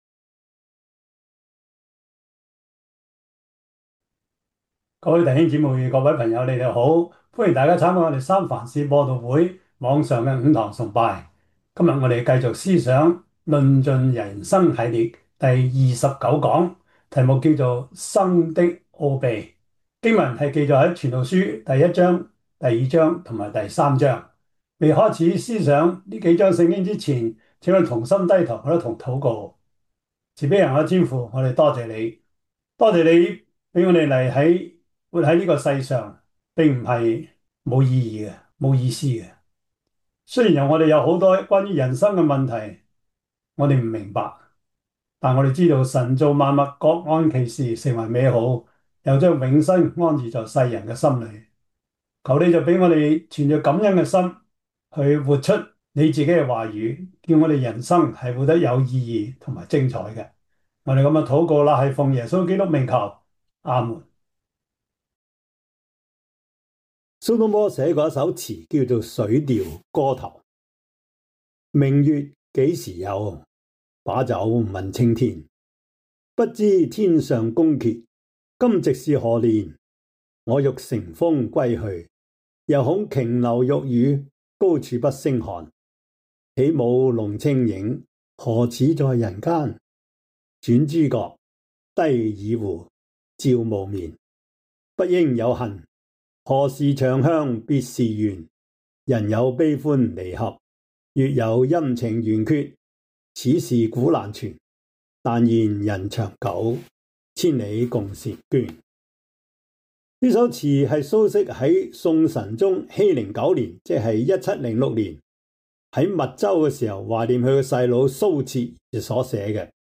傳道書 3:1-11 Service Type: 主日崇拜 傳道書 3:1-11 Chinese Union Version